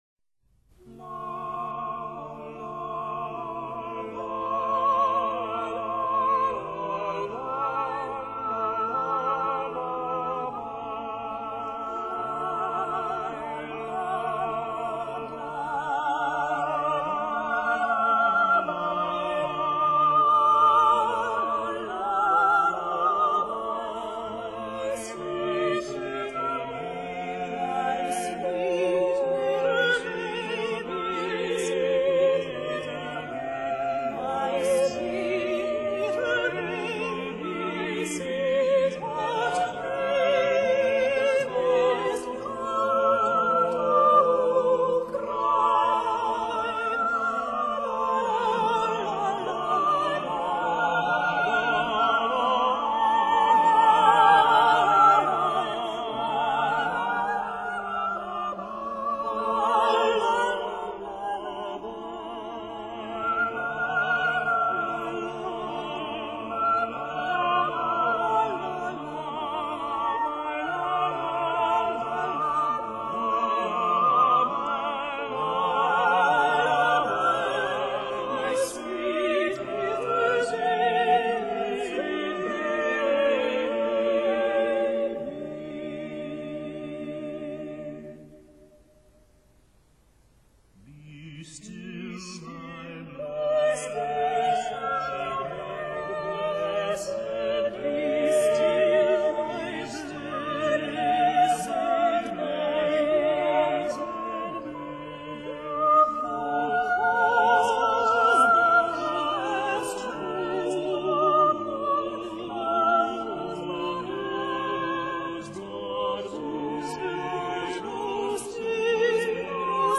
十、 颂歌（英文为Anthem，拉丁文为antiphona，古英文为Antefn anthem Antym）